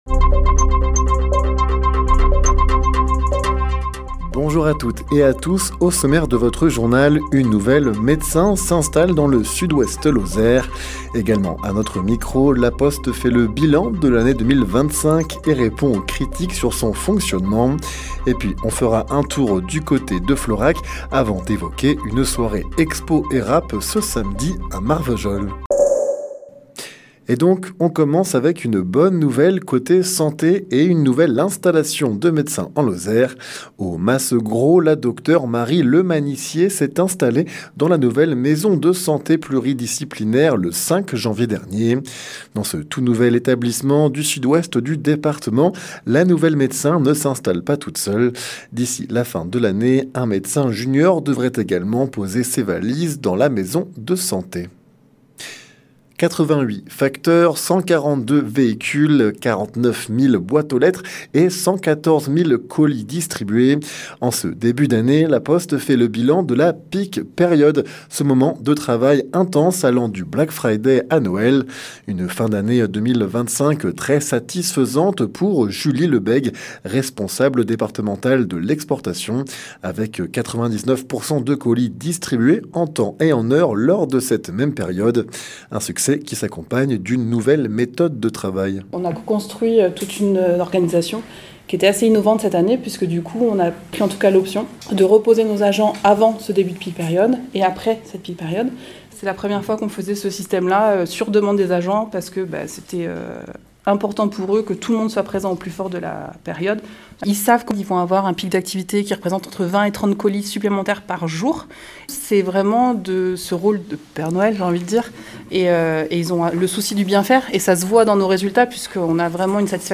Le journal sur 48FM
Les informations locales